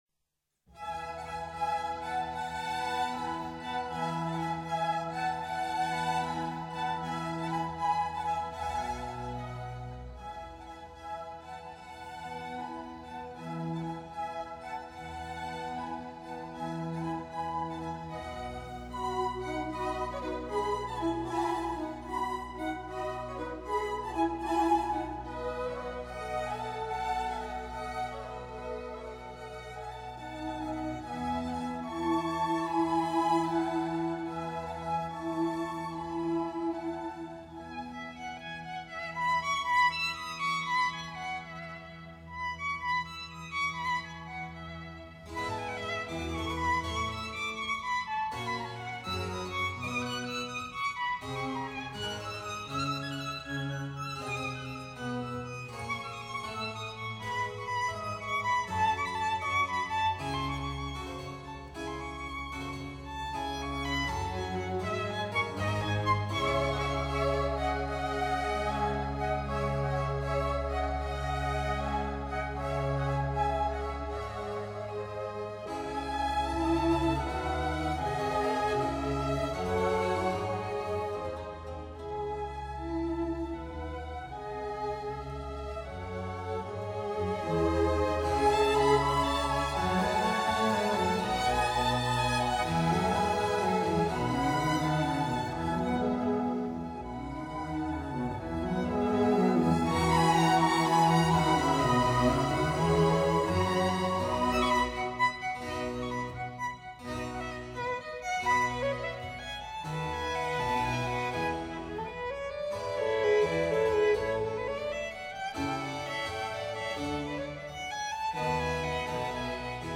乡村舞曲开始的曲调（十四行诗中的字母G）令人想起农村的风笛。小提琴中的轻快活泼的曲调，和模仿风笛。嗡嗡声的低音弦乐器中的持续音都造成这样的印象。